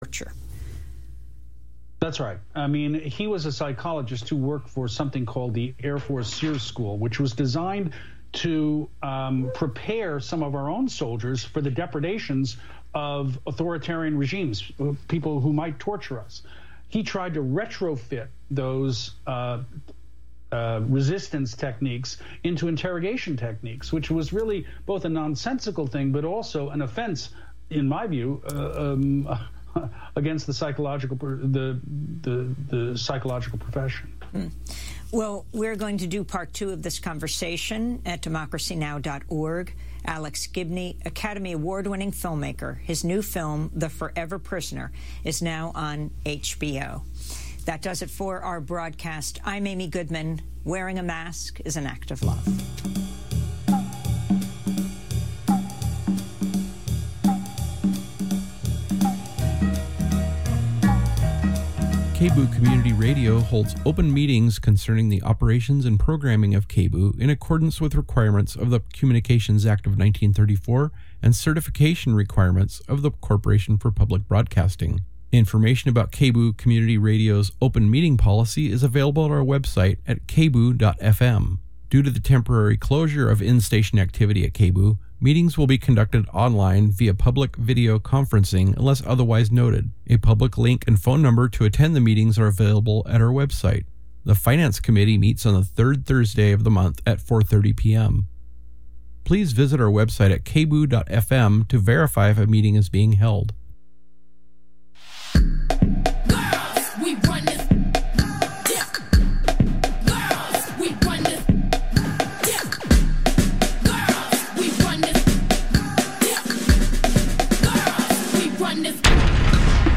Then, in the second half of the show, we ask our listeners about what their gift-giving strategy is.